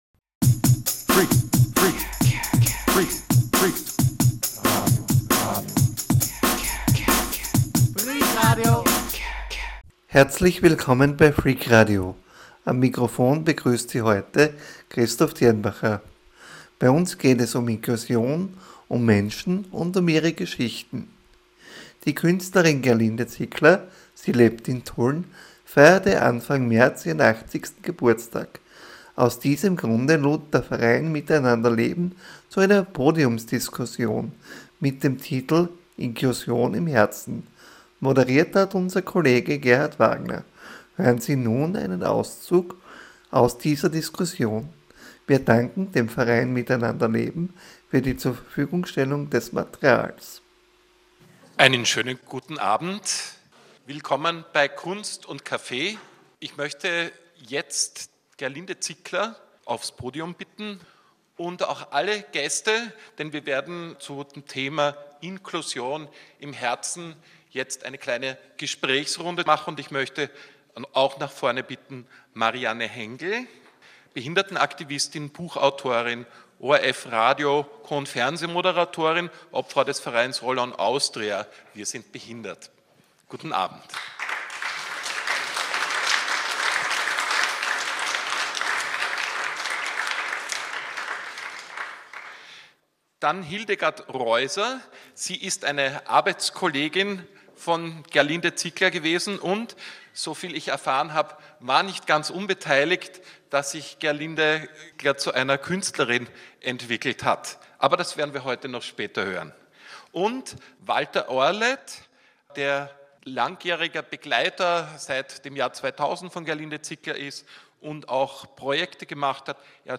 Die Jubiläumsfeier hat am 11. Oktober im liebevoll renovierten Minoritenkloster in Tulln stattgefunden. Es gab auch eine Podiumsdiskussion unter dem Motto „Inklusion im Herzen“.